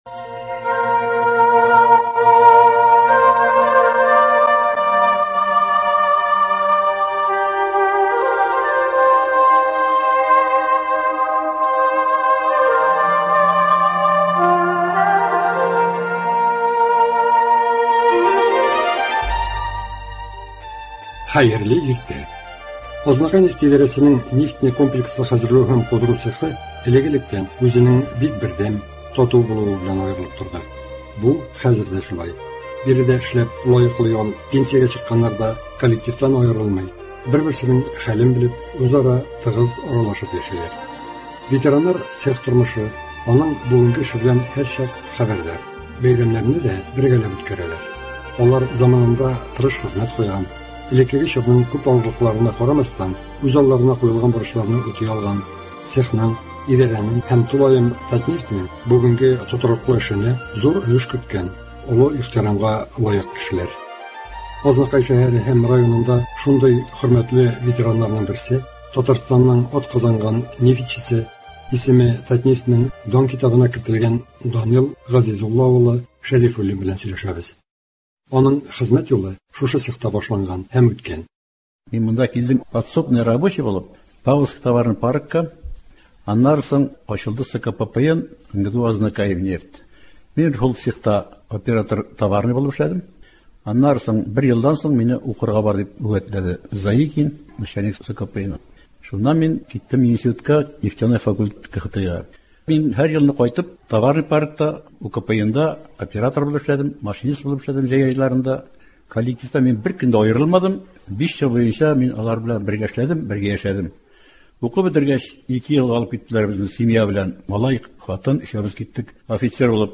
әңгәмә.